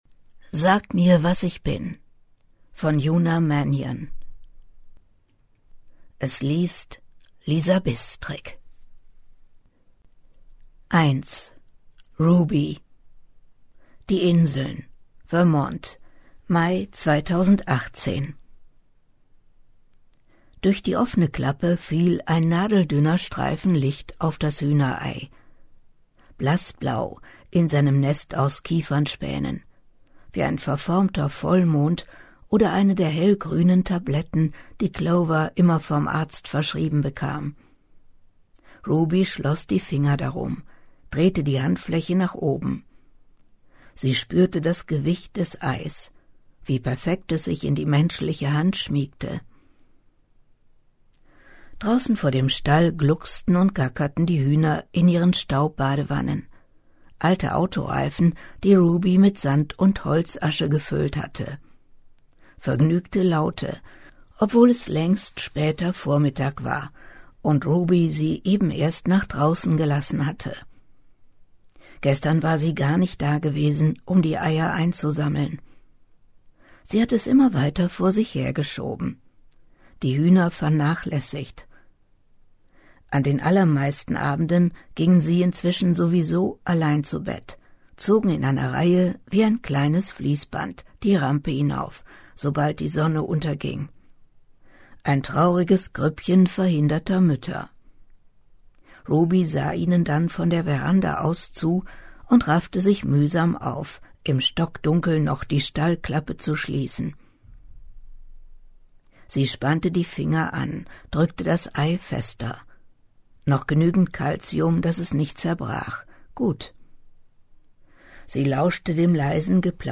Dieses kriminell spannende Familiendrama liest für Sie